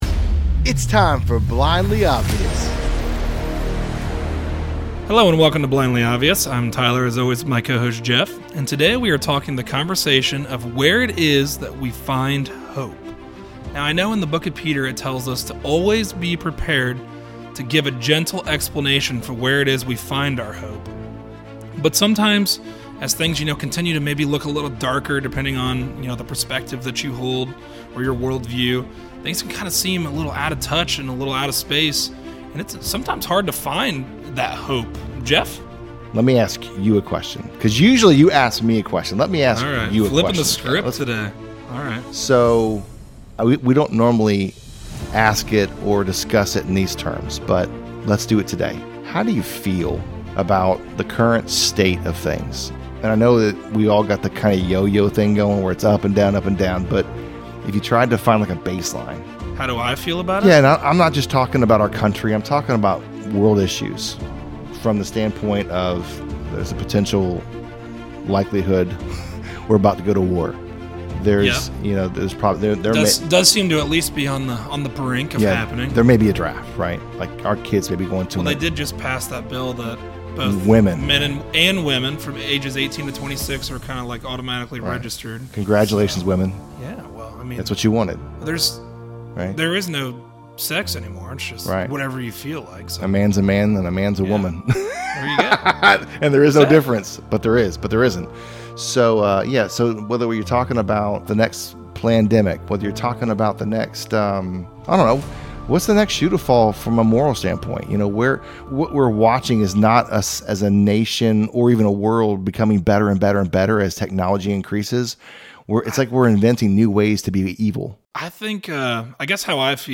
A conversation on where it is we find hope.